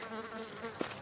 蜜蜂嗡嗡叫
描述：蜜蜂嗡嗡叫
标签： 蜂鸣器 蜜蜂
声道立体声